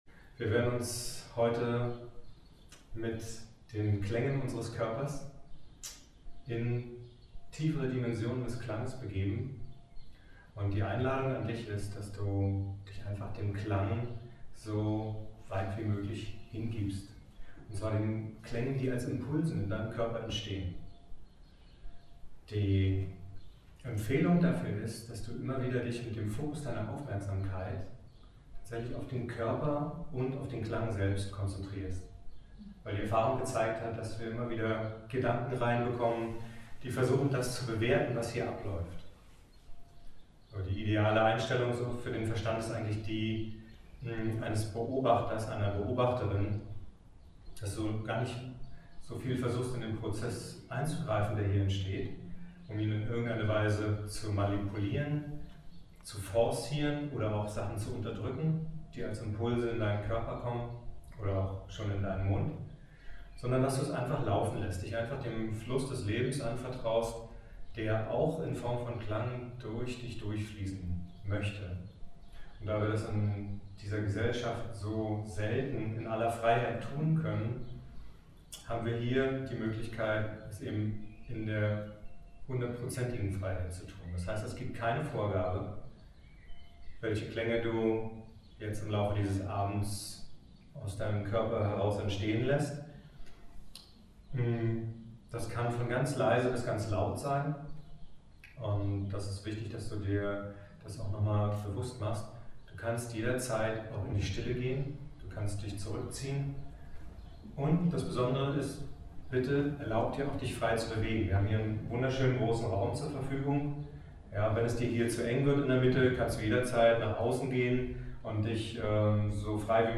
Ansprache an einem DANCING VOICES Abend